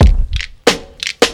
90 Bpm Breakbeat C Key.wav
Free drum groove - kick tuned to the C note. Loudest frequency: 1242Hz
90-bpm-breakbeat-c-key-GPk.ogg